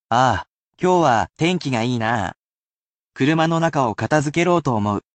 However, it will be at normal speed which may be difficult for beginners, but should assist you in getting used to the speed of the language, but this will act as useful listening practise.
[casual speech]